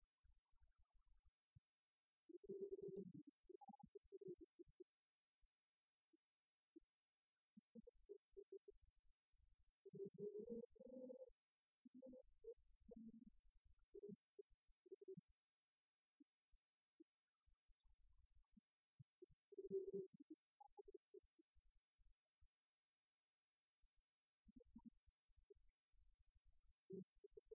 Concert de la chorale des retraités
Pièce musicale inédite